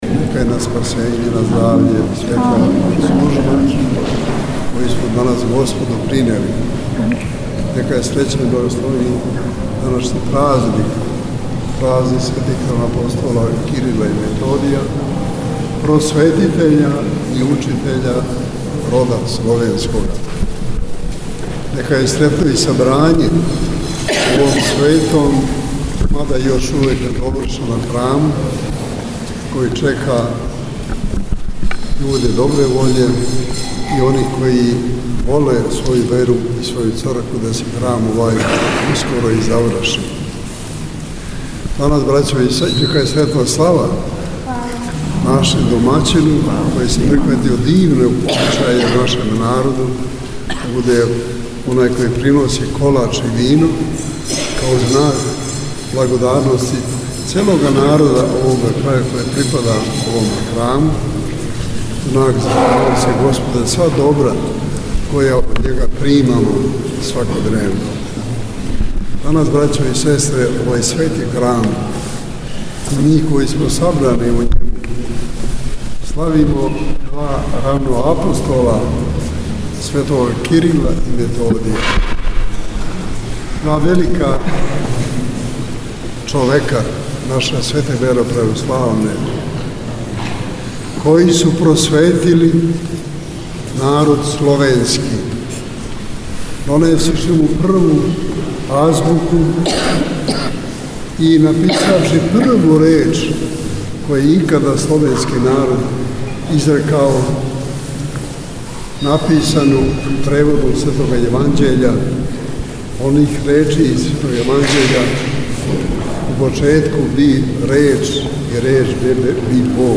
BESEDA-PATRIJARHOVA-U-JAJINCIMA.mp3